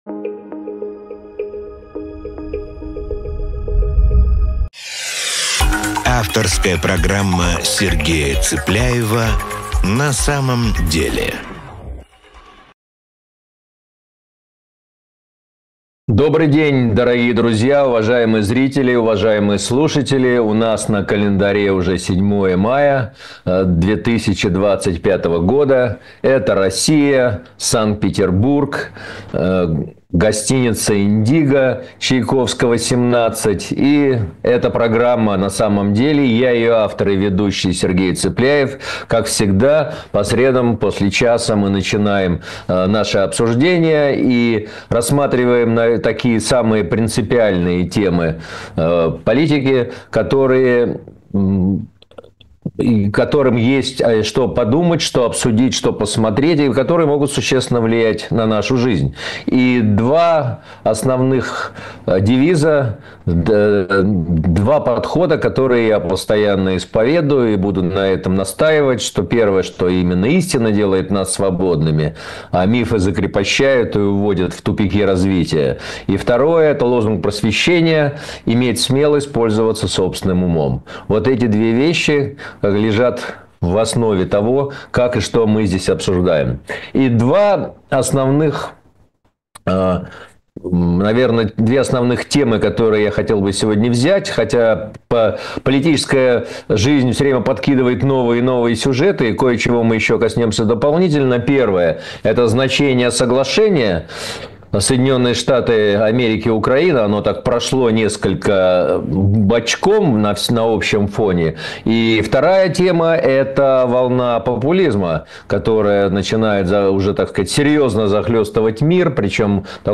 Эфир ведёт Сергей Цыпляев